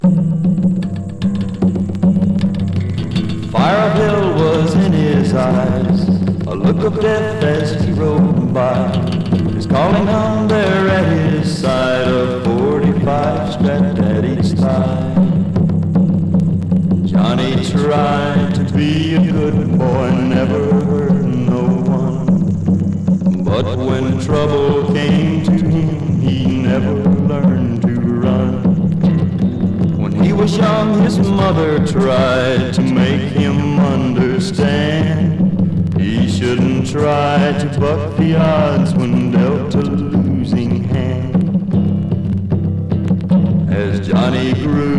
Rockabilly, Rock & Roll　Germany　12inchレコード　33rpm　Mono